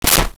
Switch 1
switch-1.wav